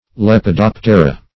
Lepidoptera \Lep`i*dop"te*ra\ (-d[o^]p"t[-e]*r[.a]), n. pl.
lepidoptera.mp3